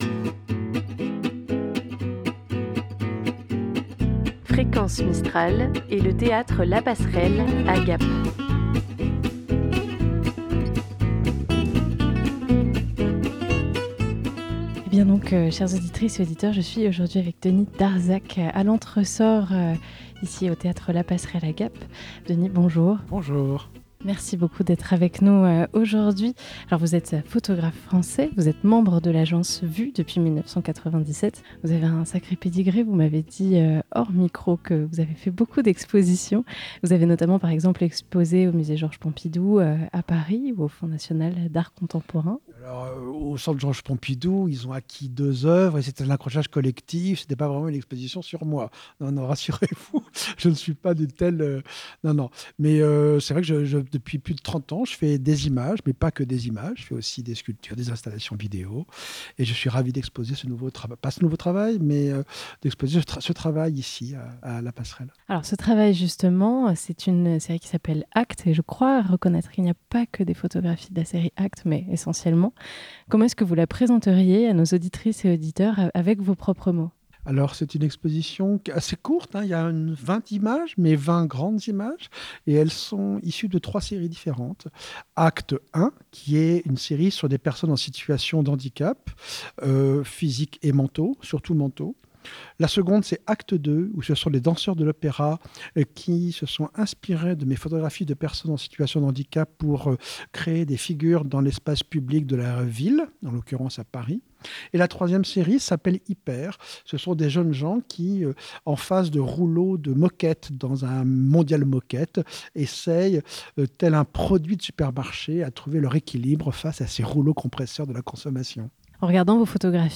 Juste avant le vernissage de son exposition, il nous a accordé un entretien : 260317 - ITW Denis Darzacq.mp3 (45.8 Mo)